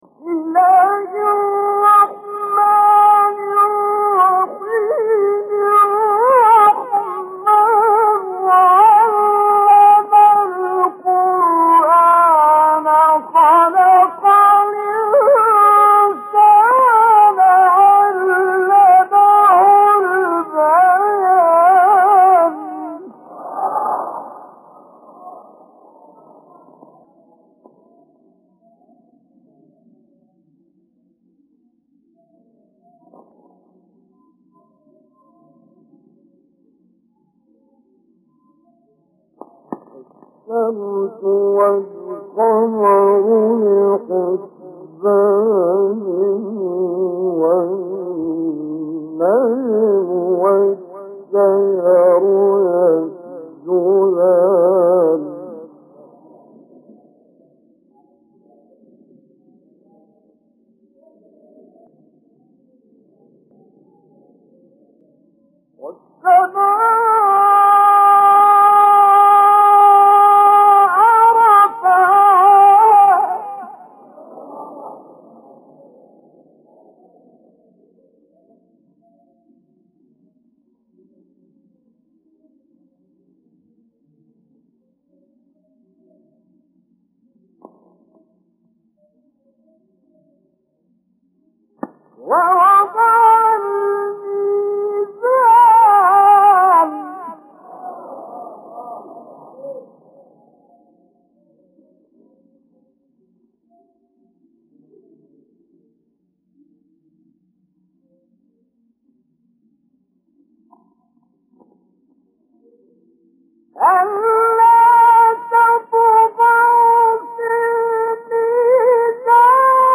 سوره : رحمن آیه: 1-16 استاد : کامل یوسف مقام : مرکب خوانی (بیات * صبا * بیات) قبلی بعدی